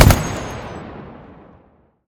gun-turret-shot-5.ogg